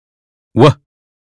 us_phonetics_sound_we_2023feb.mp3